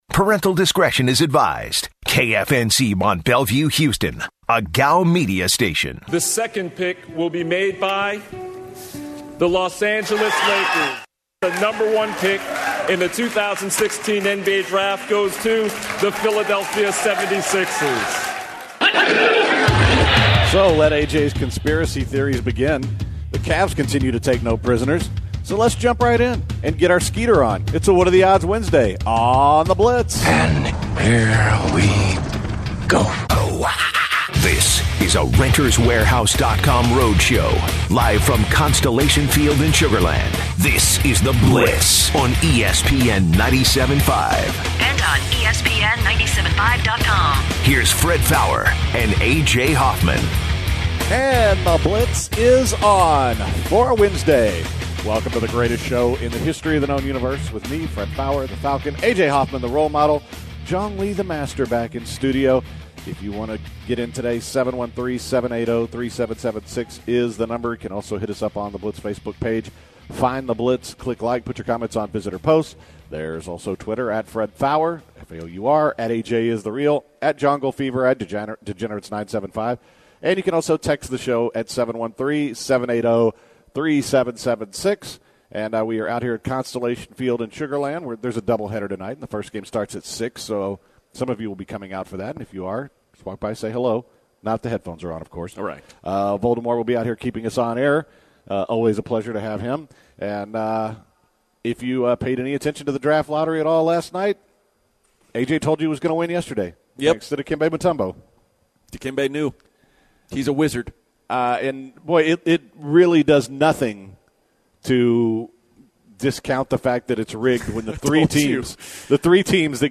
live from Constellation Field in Sugarland, Texas.